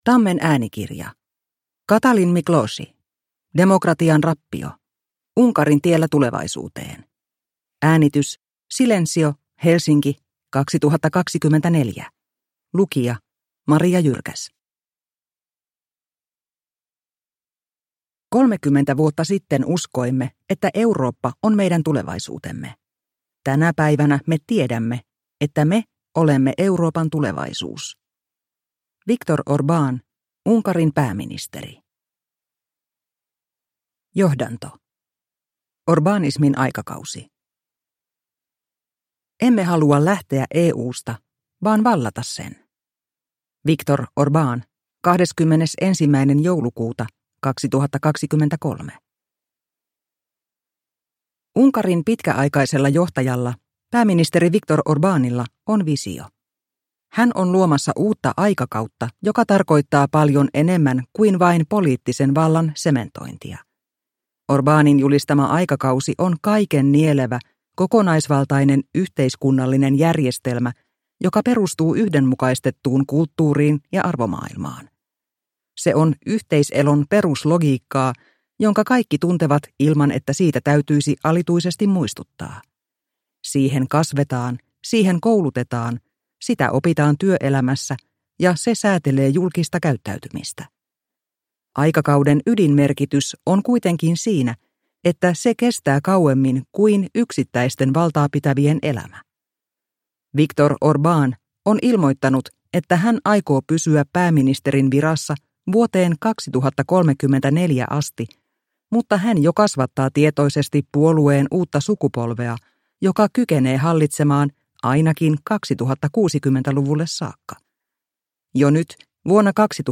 Demokratian rappio – Ljudbok